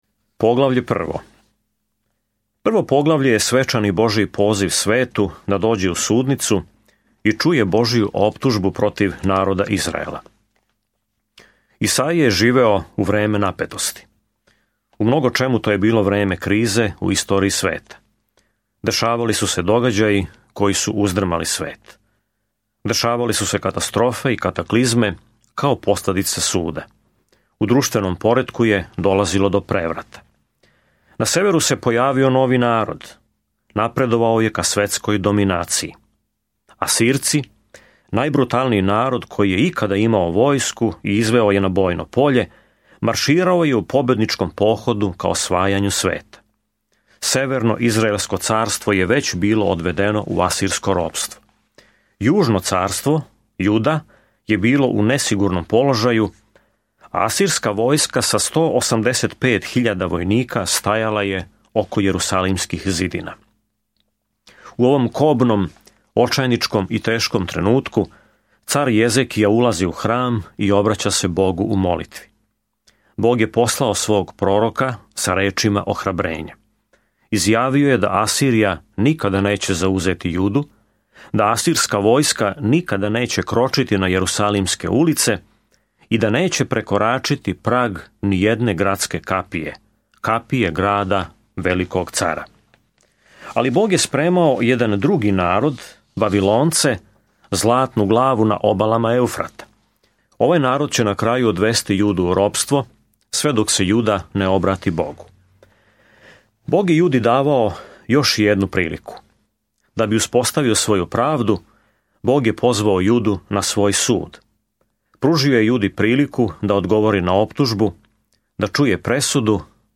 Sveto Pismo Knjiga proroka Isaije 1:1-14 Dan 1 Započni ovaj plan Dan 3 O ovom planu Назван „пето јеванђеље“, Исаија описује долазећег краља и слугу који ће „носити грехе многих“ у мрачно време када ће политички непријатељи завладати Јудом. Свакодневно путујте кроз Исаију док слушате аудио студију и читате одабране стихове из Божје речи.